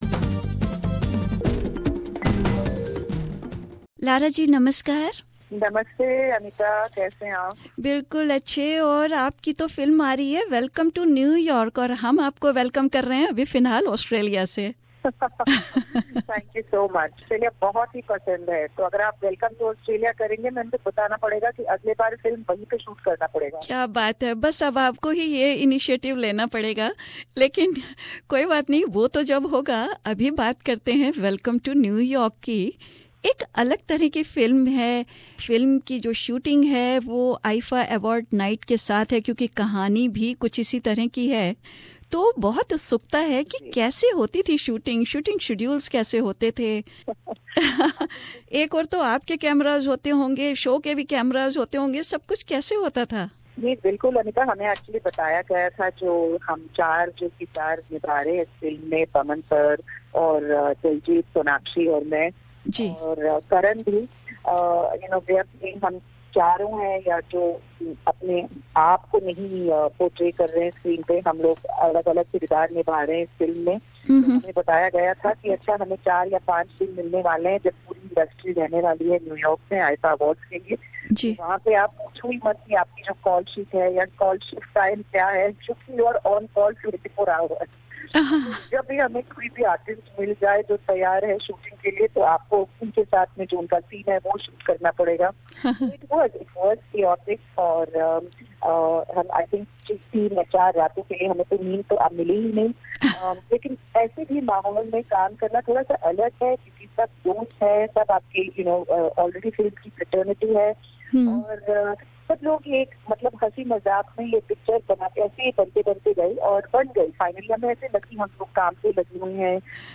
Bollywood actress Lara Dutta with a laughter explained the comical yet a serious scenario of destroying the whole event that she so passionately had planned.
EXCLUSIVE INTERVIEW WITH LARA DUTTA Bollywood actress Lara Dutta with a laughter explained the comical yet a serious scenario of destroying the whole event that she so passionately had planned.